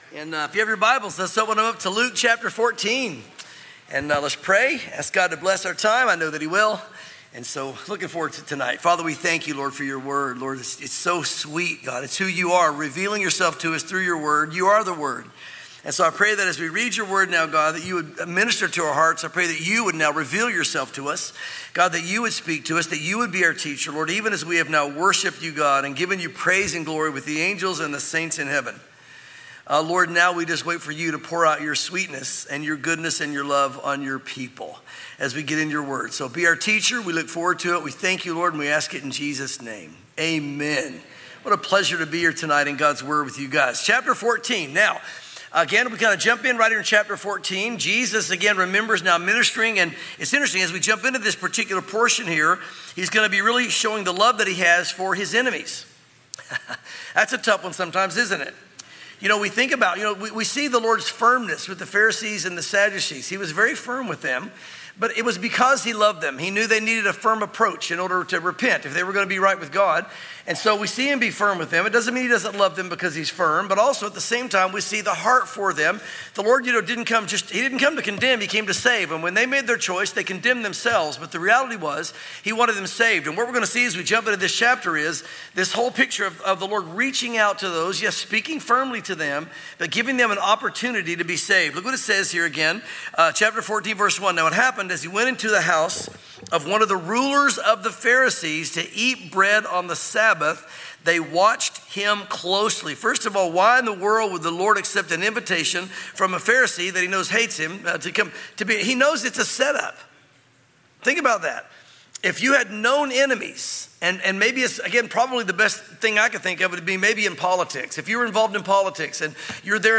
sermons Luke Chapters 14 &15